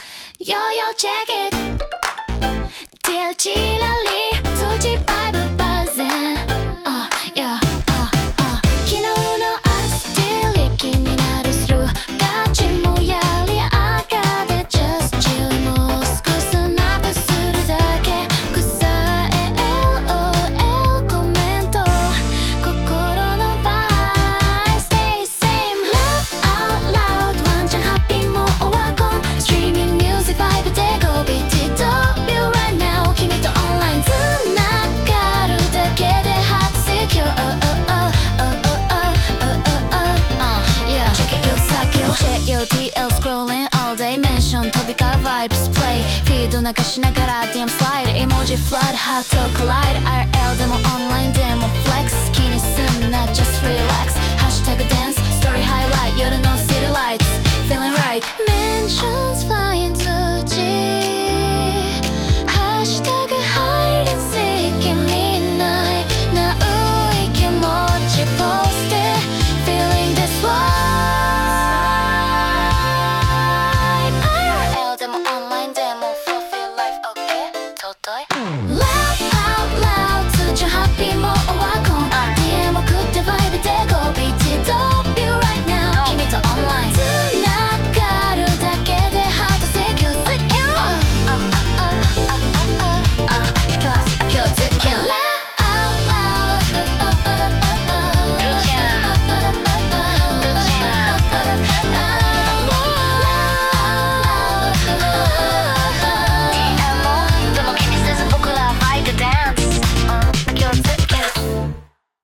イメージ：J-POP,K-POP,女性ボーカル,シティポップ,ラップパート,80年代Jポップファンク,シンセポップ